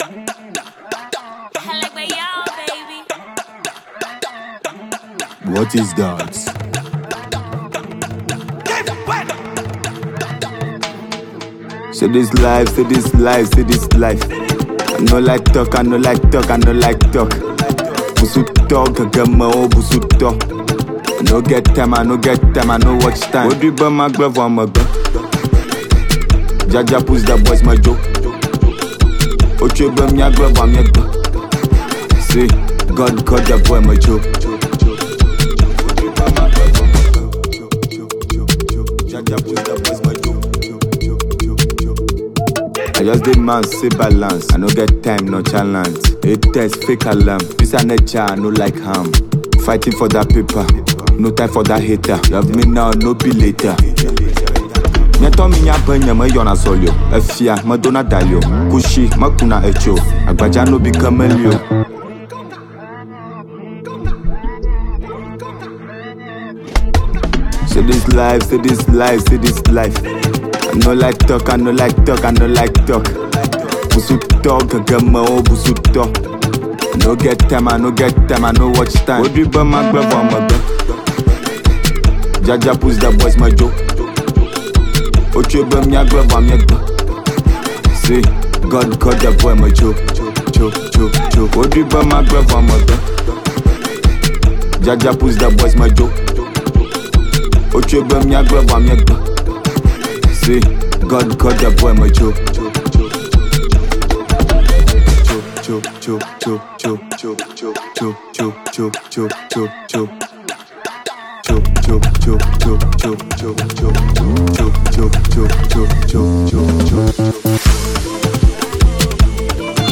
impactful track with beautiful rhythms